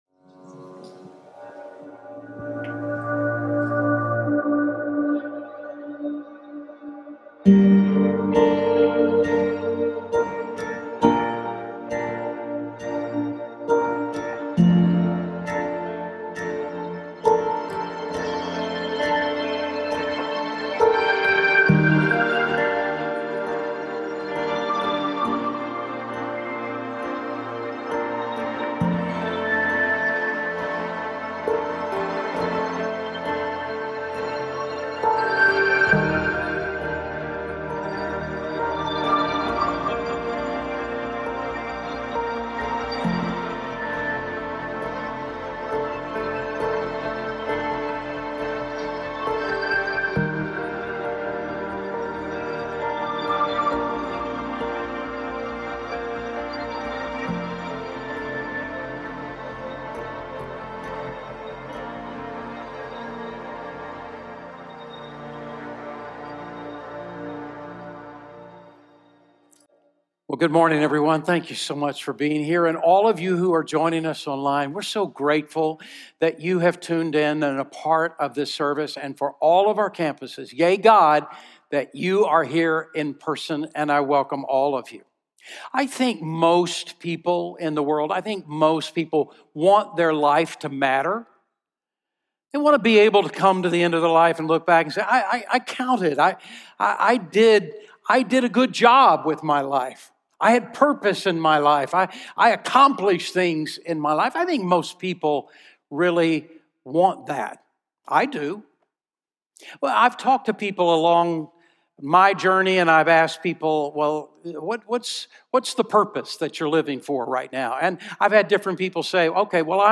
Here you can watch Sugar Creek sermons live online, or look back at previous sermons.